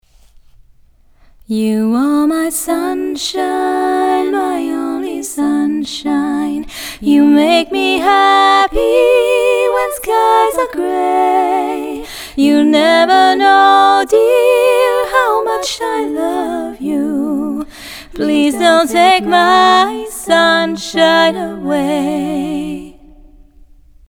Singing Showreel
Female
Warm